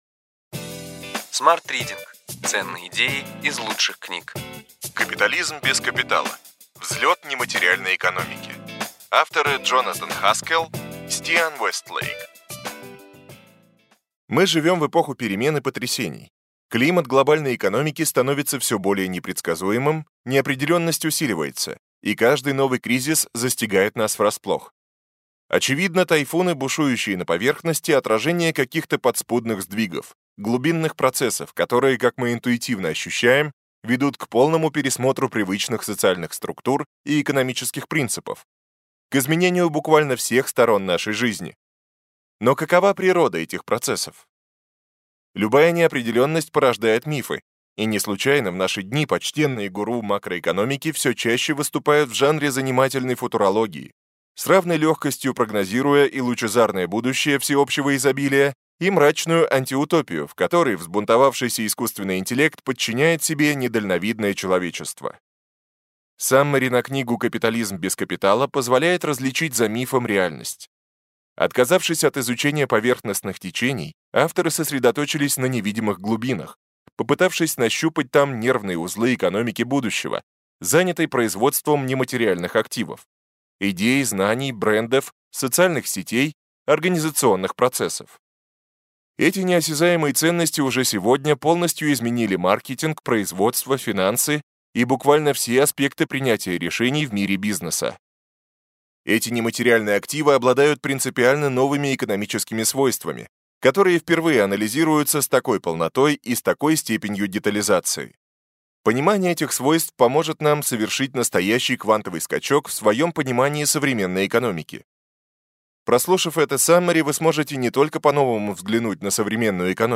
Аудиокнига Ключевые идеи книги: Капитализм без капитала: взлет нематериальной экономики.